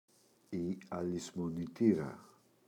αλησμονητήρα, η [alizmoniꞋtira]